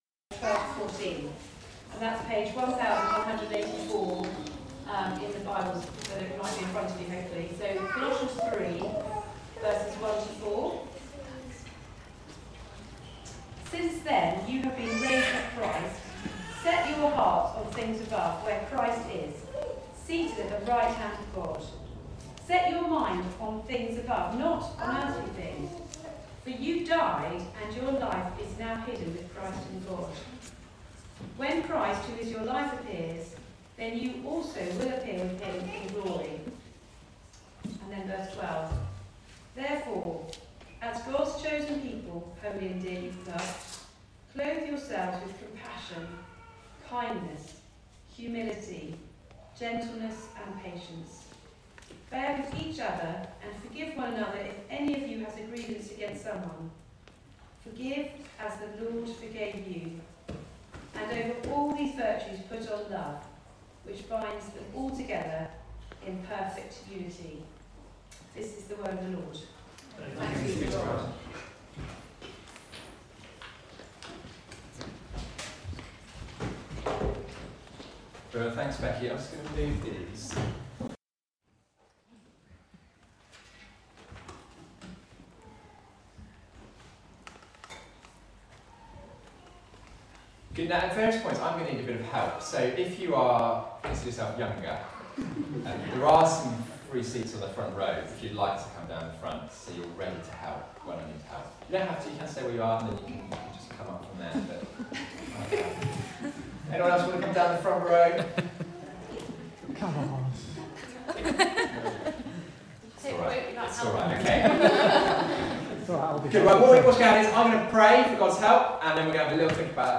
Colossians Passage: Colossians 3:12-14 Service Type: Weekly Service at 4pm « Hidden with Christ